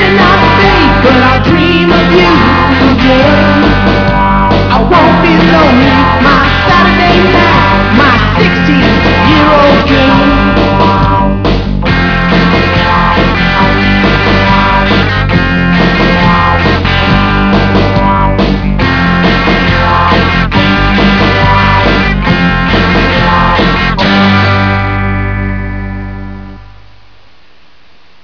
experimental, strange,and exotic sound